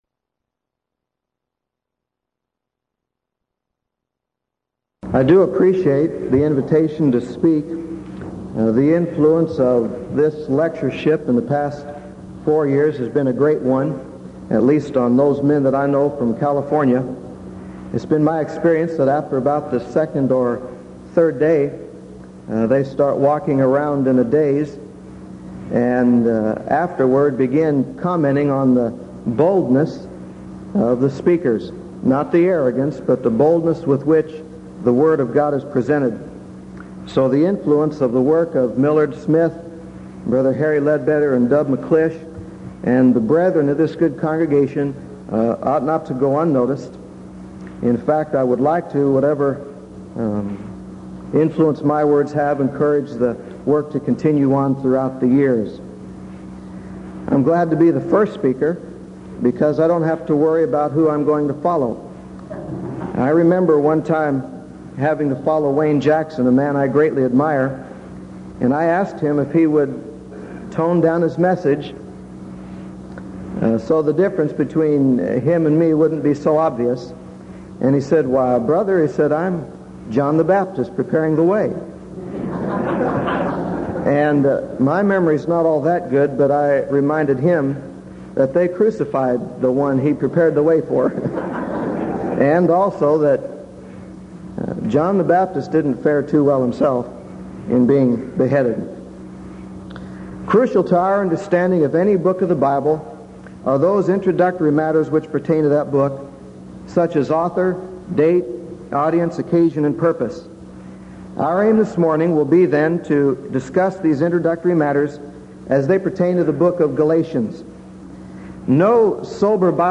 1986 Denton Lectures
lecture